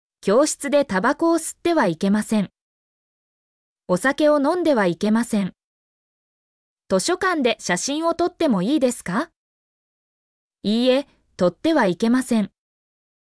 mp3-output-ttsfreedotcom-30_8n1gvEgA.mp3